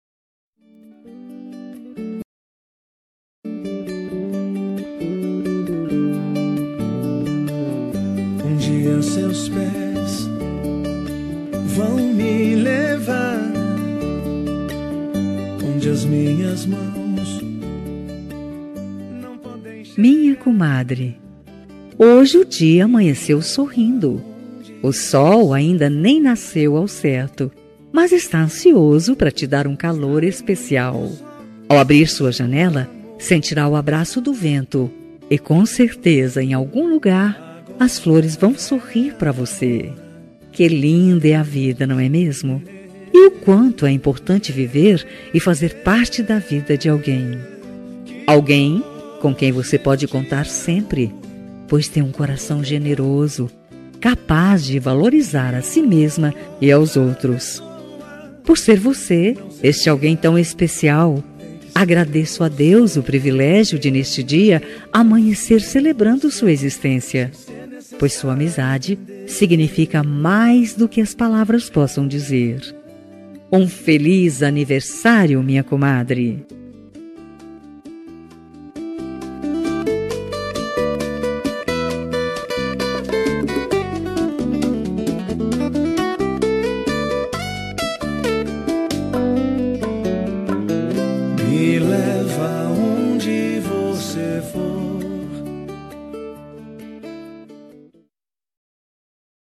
Aniversário de Comadre – Voz Feminina – Cód: 202143